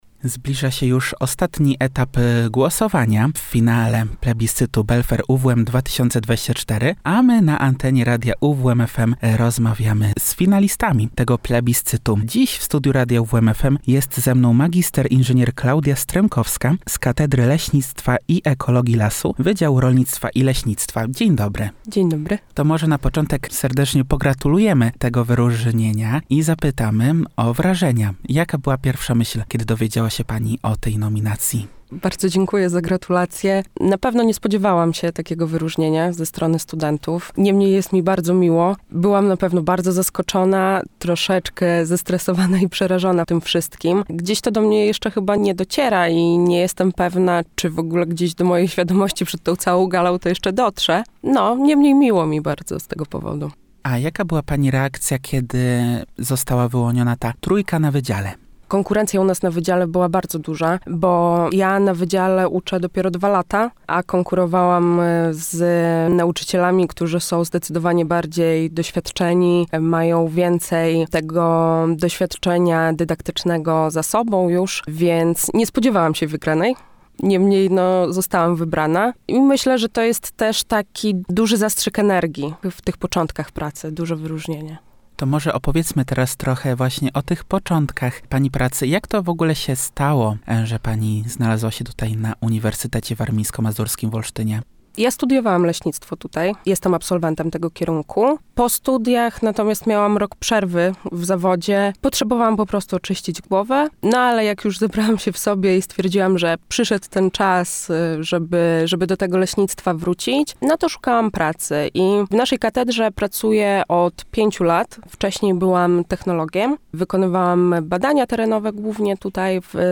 – mówi finalistka, a jakie są jej plany na najbliższe miesiące?